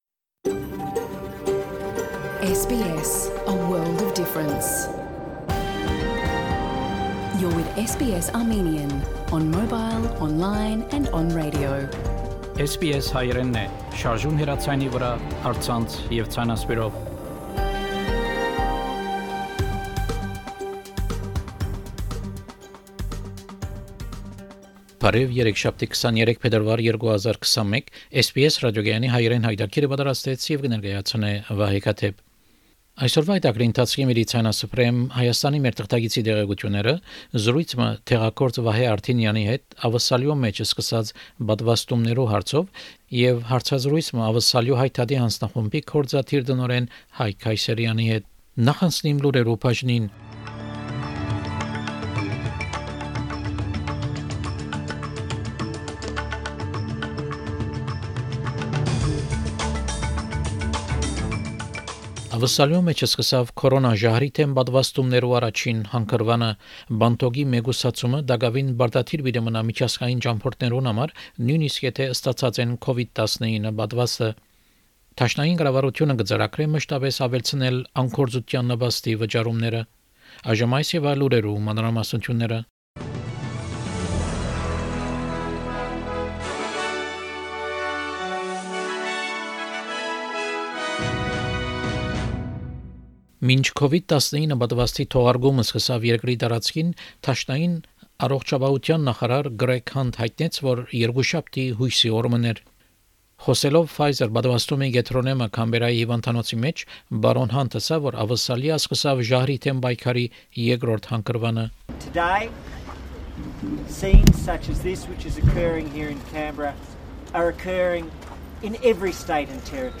SBS Armenian news bulletin – 23 February 2021
SBS Armenian news bulletin from 23 February 2021 program.